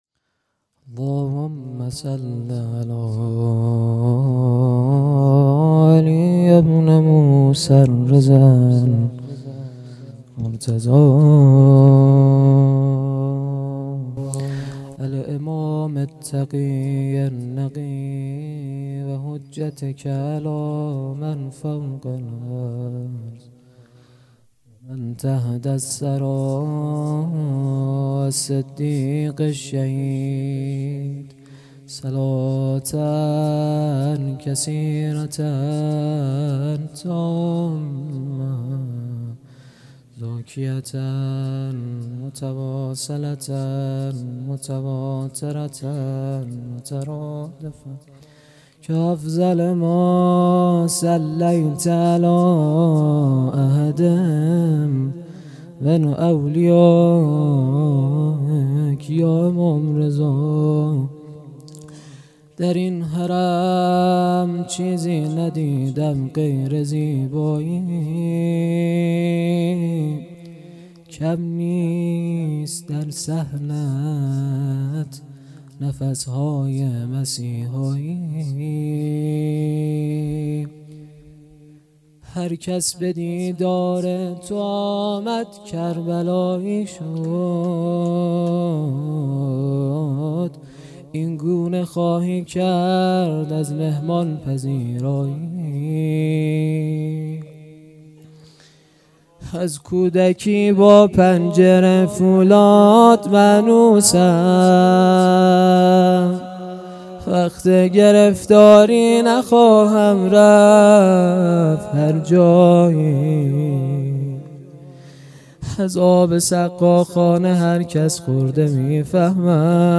هيأت یاس علقمه سلام الله علیها
شهادت امام رضا علیه السلام